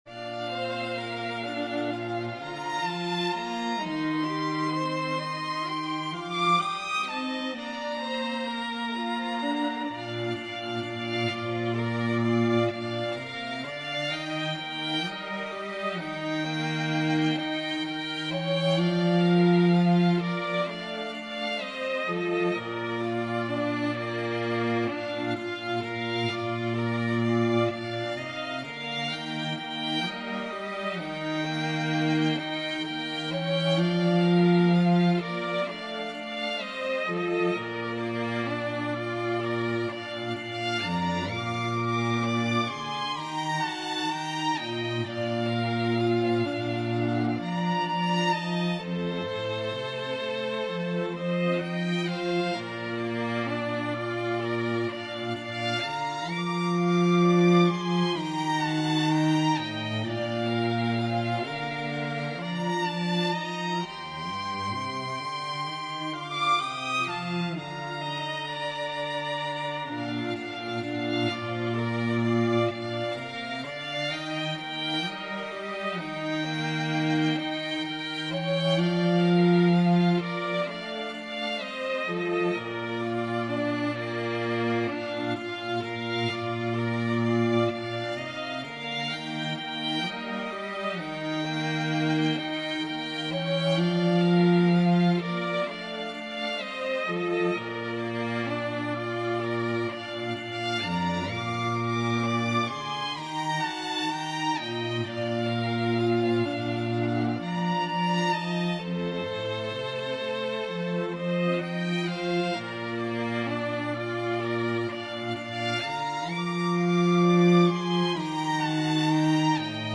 Genre: Folk/Traditional